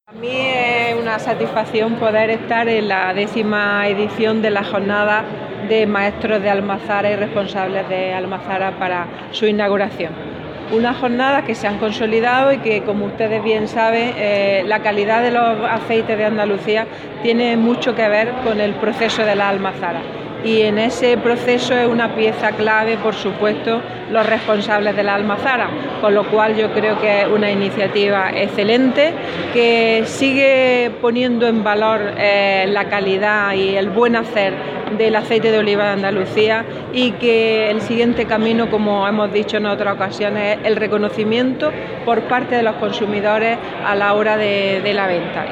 Carmen Ortiz, en el X Encuentro de Maestros de Almazara.
Declaraciones Carmen Ortiz en Encuentro de Maestros de Almazara.